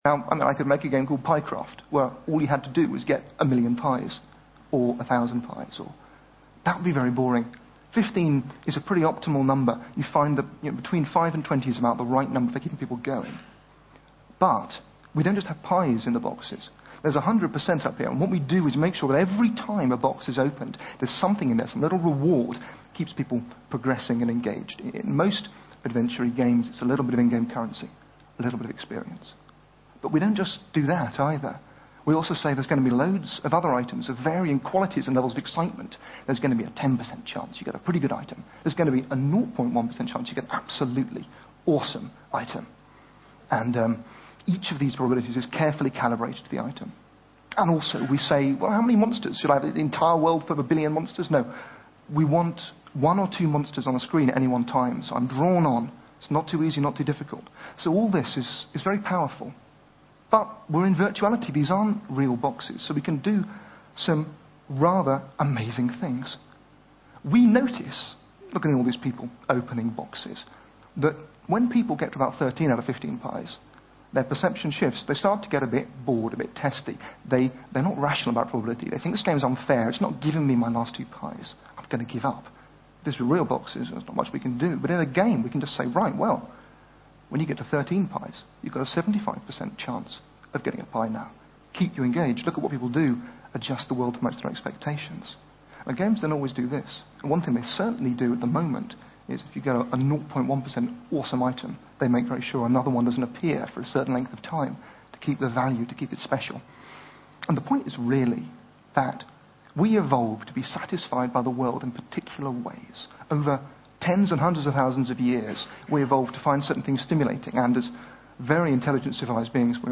TED演讲:游戏奖励大脑的7种方式(3) 听力文件下载—在线英语听力室